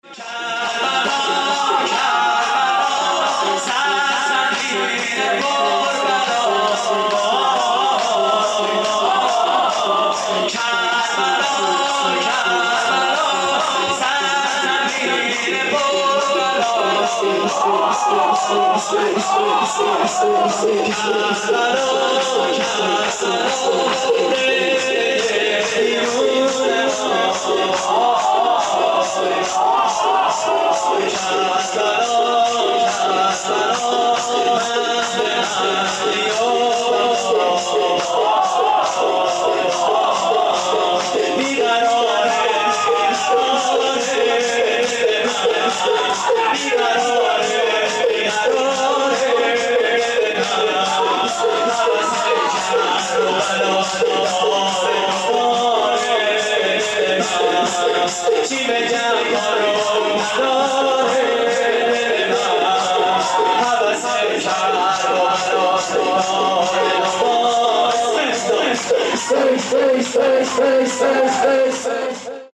شور: سرزمین پربلا
مراسم عزاداری اربعین حسینی